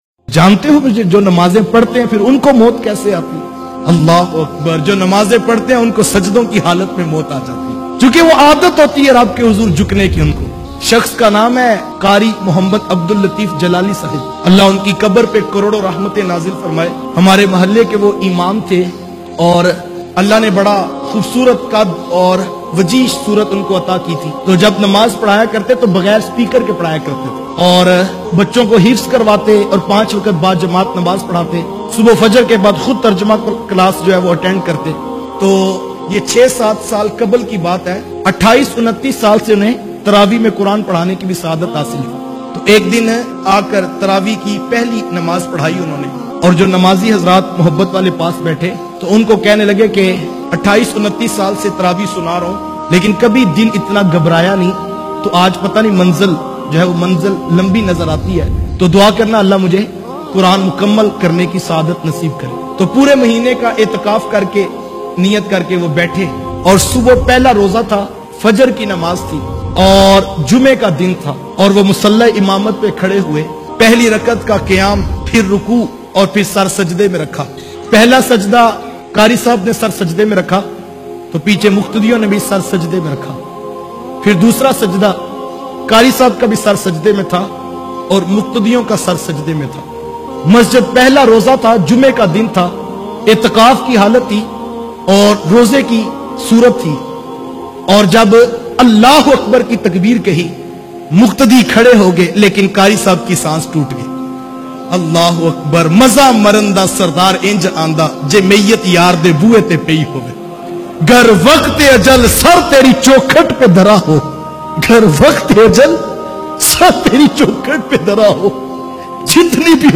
Numaz sy muhabbat ka ajar bayan mp3